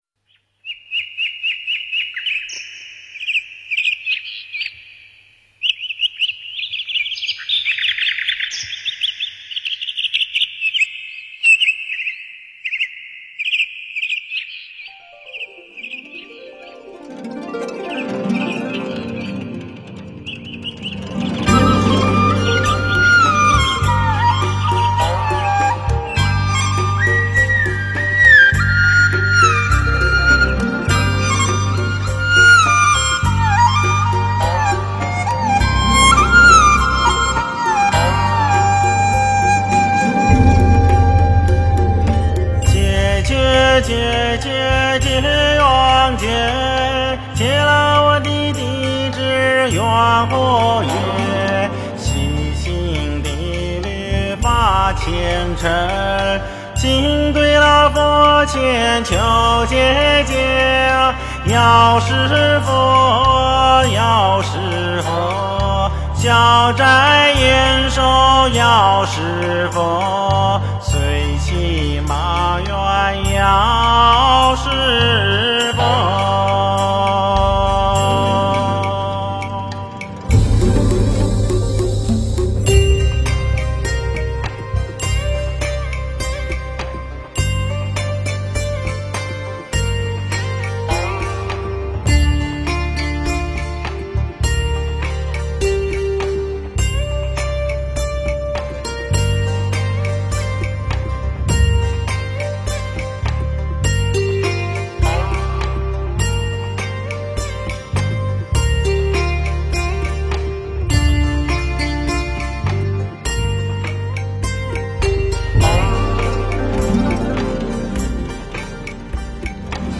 佛音 诵经 佛教音乐 返回列表 上一篇： 密集玛 下一篇： 炉香赞 相关文章 Nagas(龙王咒